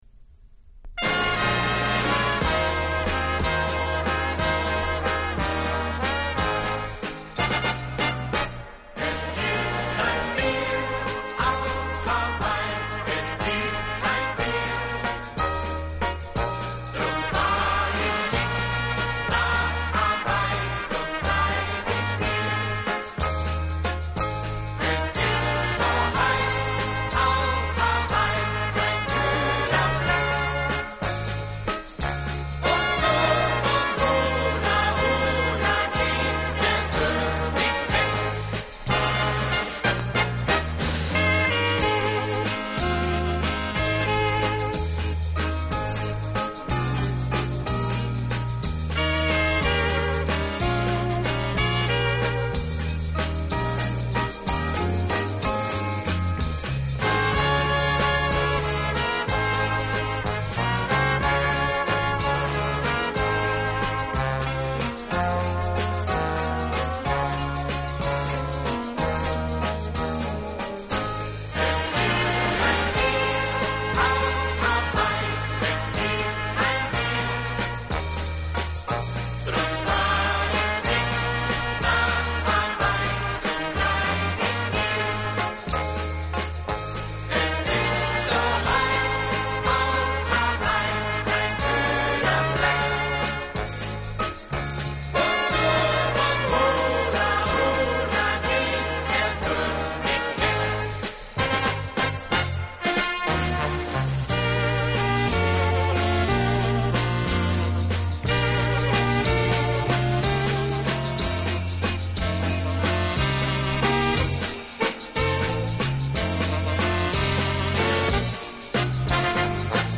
(Walzer)